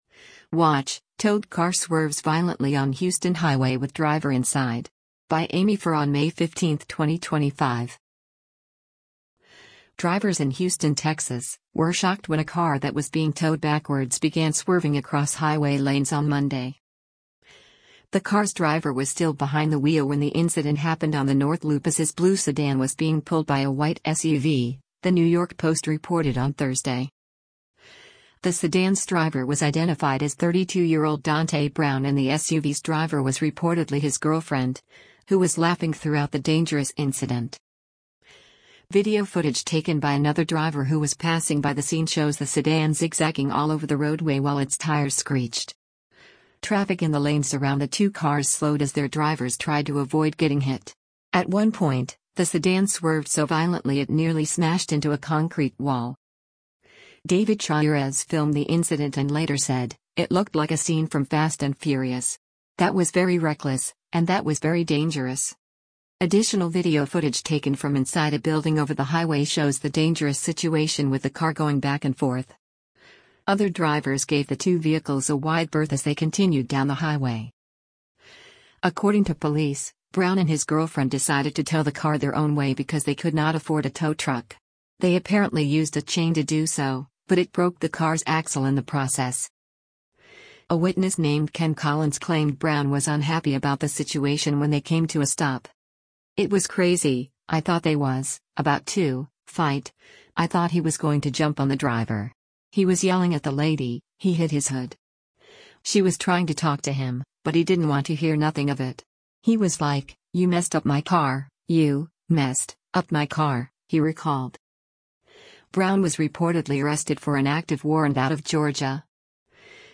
Video footage taken by another driver who was passing by the scene shows the sedan zig-zagging all over the roadway while its tires screeched.